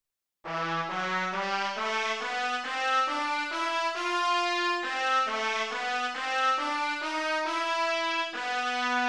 MIDI-Audio-Datei
Brass Introduction